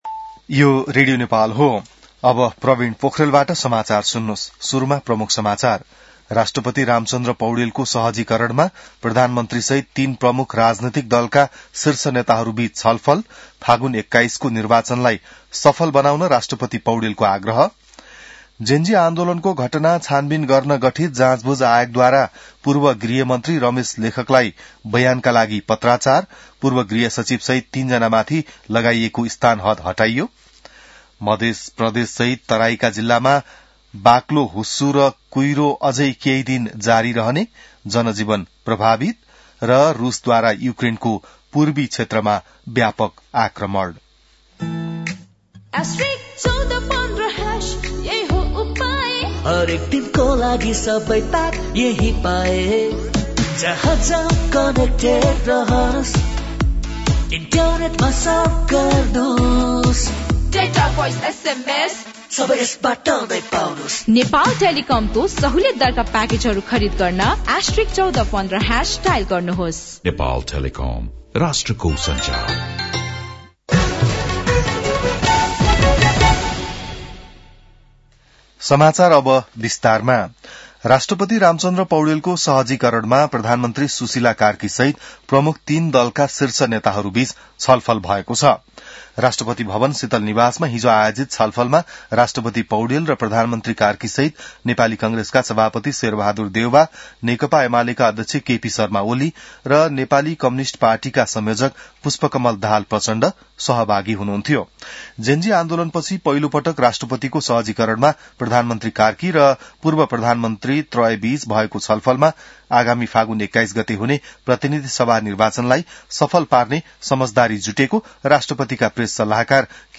बिहान ७ बजेको नेपाली समाचार : ९ पुष , २०८२